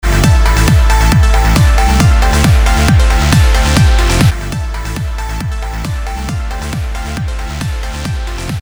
Маленький трек, начало на обычной громкости, далее снижение на 10 дБ.
10db.mp3